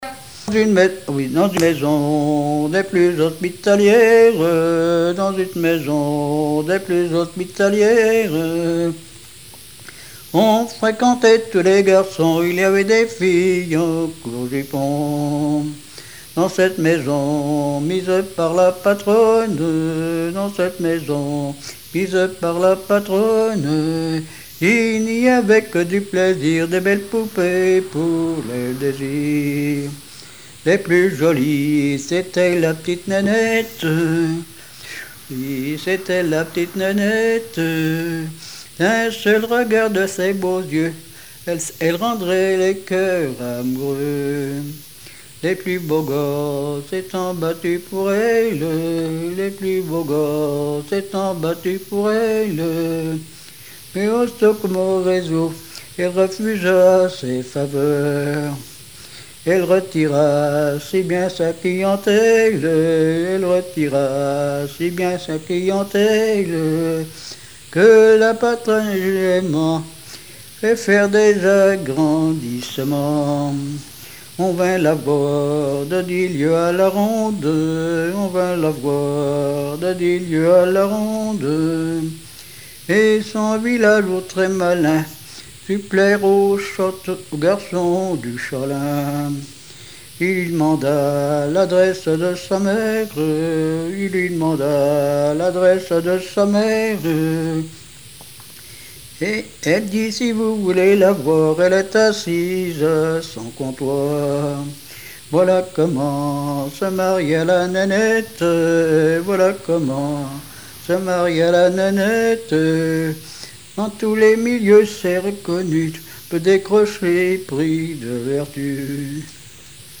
Mémoires et Patrimoines vivants - RaddO est une base de données d'archives iconographiques et sonores.
Répertoire de chansons populaires et traditionnelles
Pièce musicale inédite